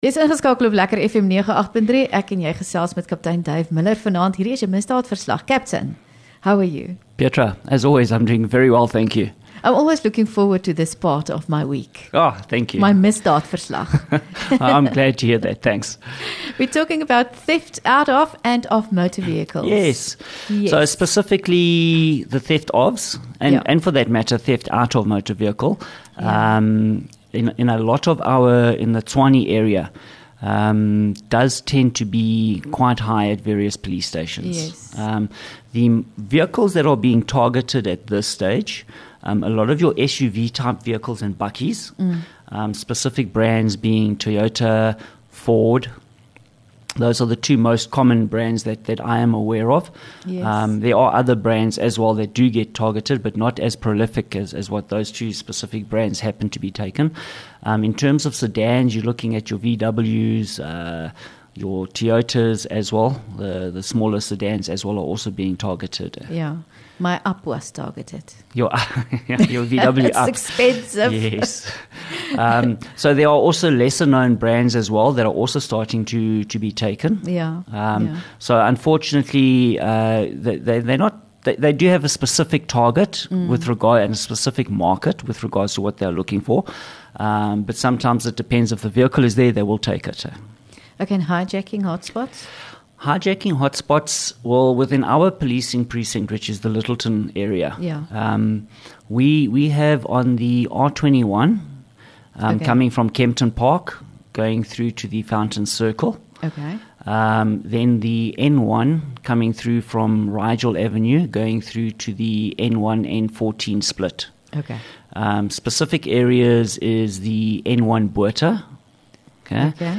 LEKKER FM | Onderhoude 9 Jul Misdaadverslag